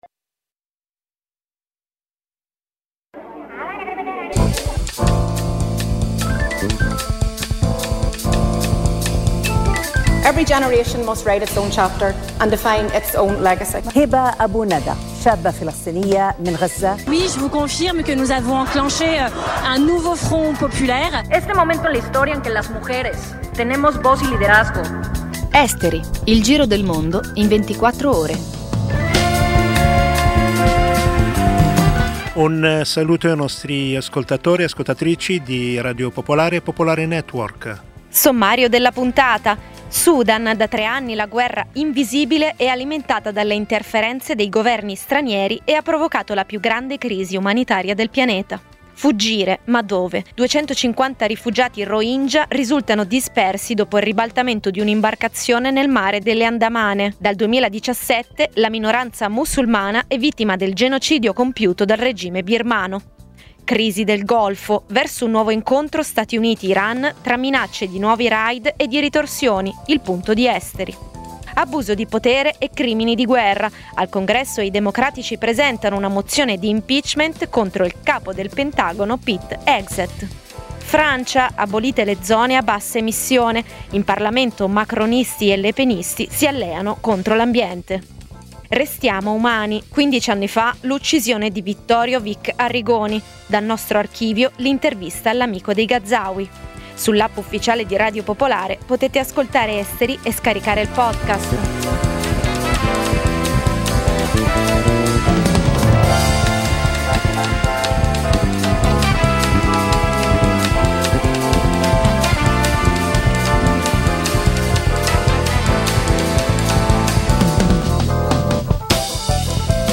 Il programma combina notizie e stacchi musicali, offrendo una panoramica variegata e coinvolgente degli eventi globali.